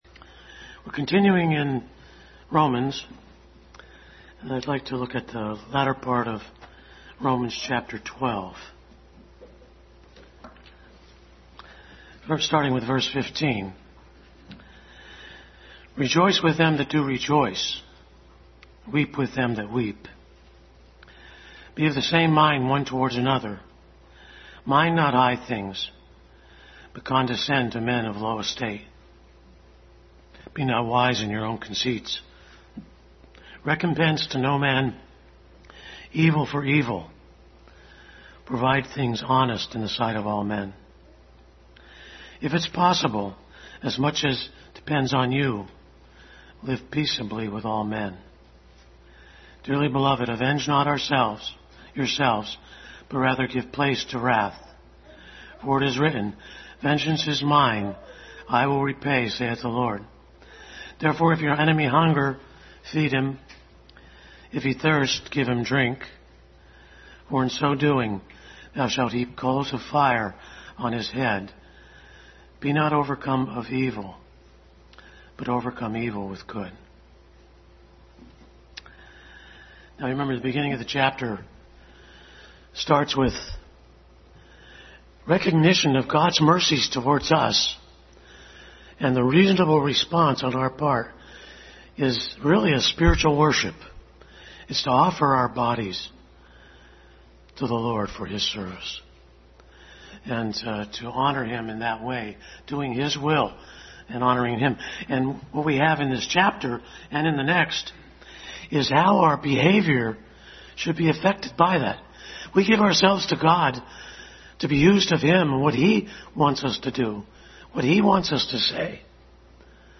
Adult Sunday School Class continued study in Romans.
1 Peter 5:5 Service Type: Sunday School Adult Sunday School Class continued study in Romans.